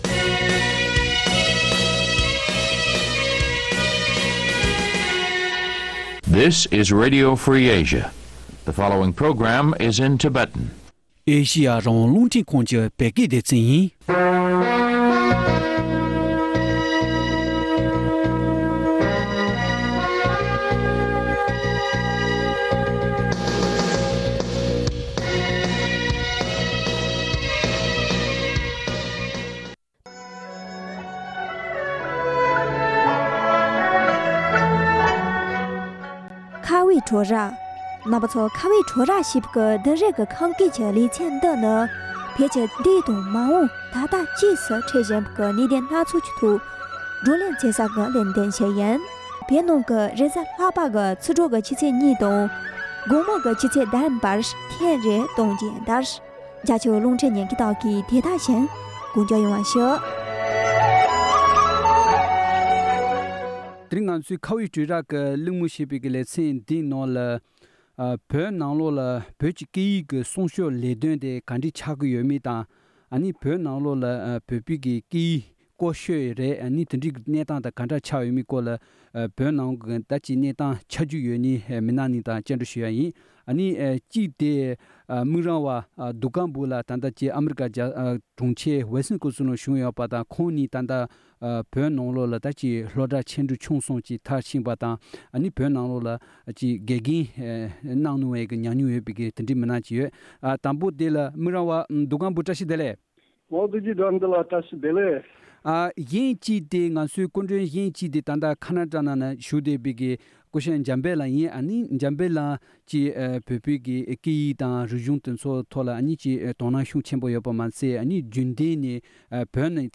གླིང་མོལ་ཞུས་པ་ཞིག་གསན་རོགས་གནང་།